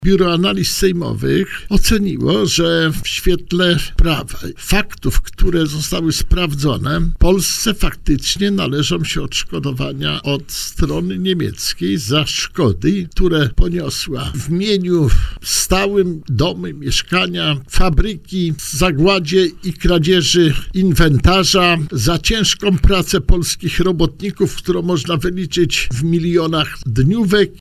Reparacje za szkody poniesione podczas drugiej wojny światowej były tematem rozmowy w poranku „Siódma9” na antenie Radia Warszawa.